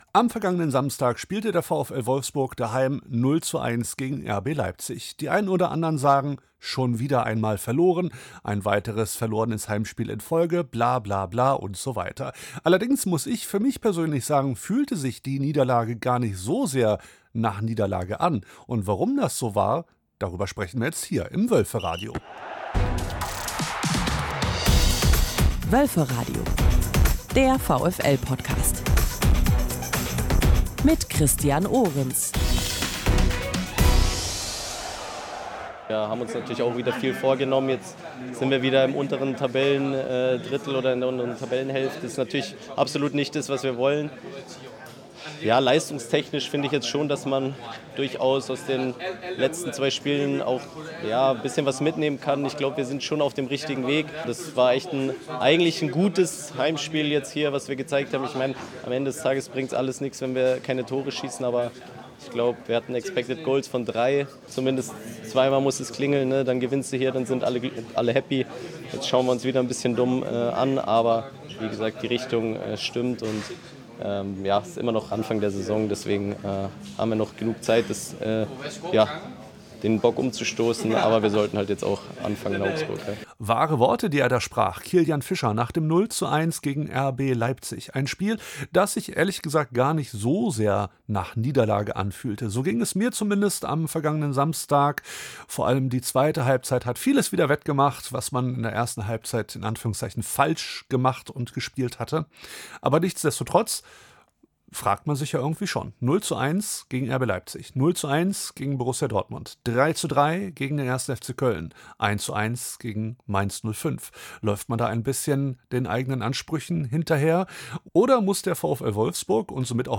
Im “FanTalk” plaudern die beiden über das hinter uns liegende Spiel, über Schieri-Entscheidungen und Video Assists, Joker-Spieler und der bevorstehenden Auswärtsfahrt zum FC Augsburg.